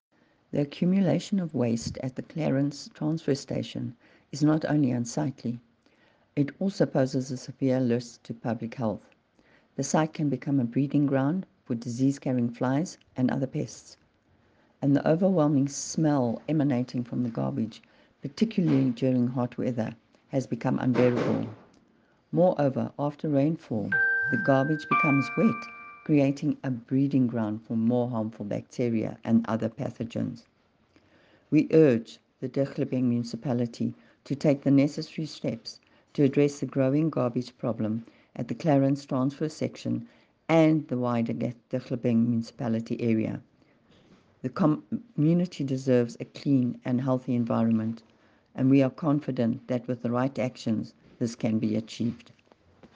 English soundbite by Cllr Irene Rugheimer and